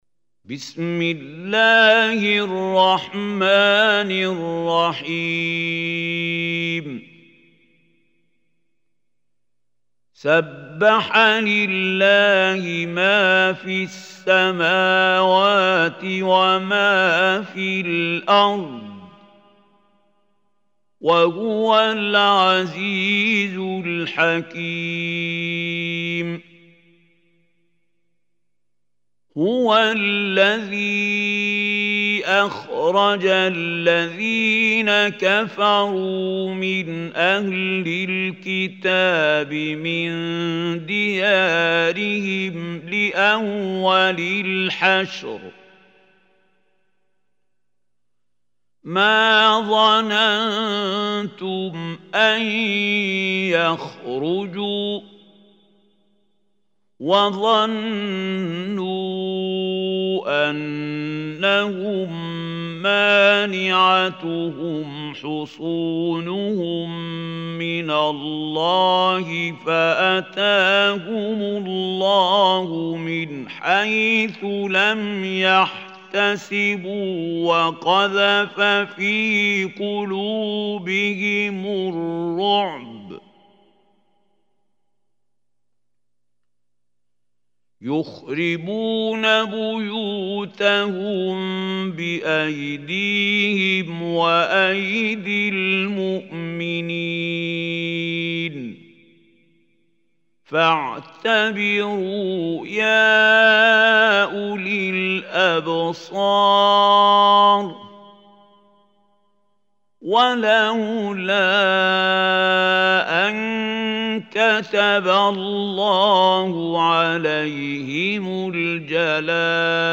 Surah Hashr Recitation by Mahmoud Khalil Hussary
Surah Hashr is 59 surah of Holy Quran. Listen or play online mp3 tilawat / recitation in Arabic in the beautiful voice of Mahmoud Khalil Al Hussary.